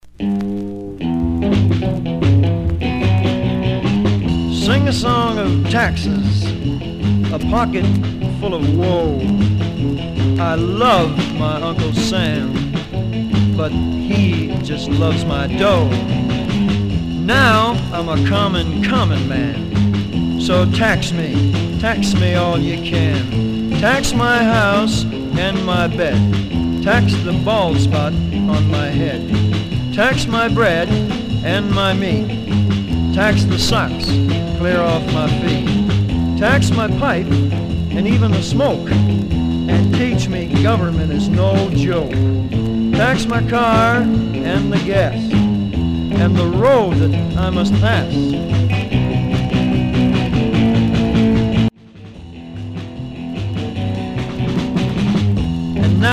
Some surface noise/wear
Mono
Rockabilly